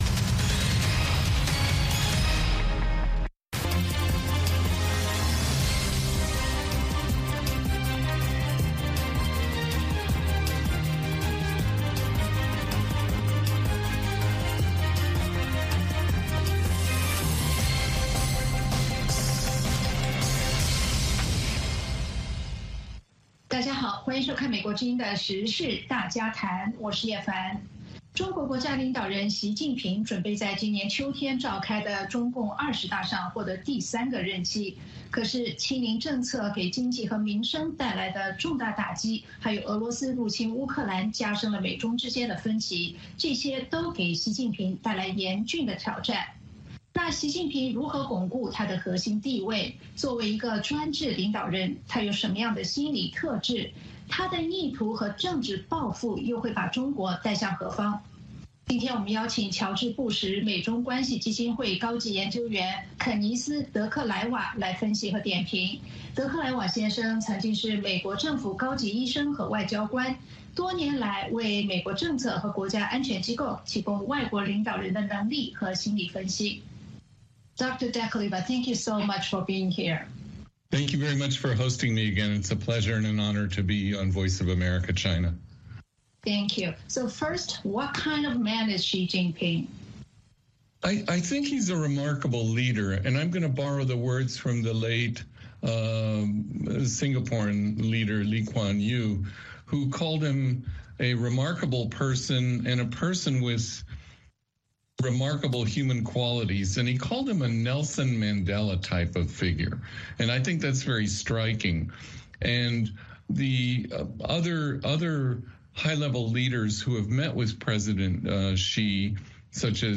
美国之音中文广播于北京时间每周日晚上9点播出《海峡论谈》节目(电视、广播同步播出)。《海峡论谈》节目邀请华盛顿和台北专家学者现场讨论政治、经济等各种两岸最新热门话题。